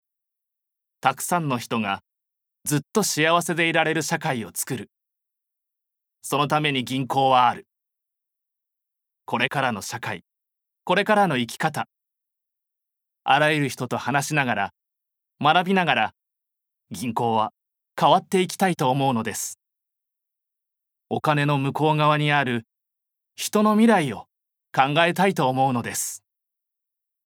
ボイスサンプル
ナレーション３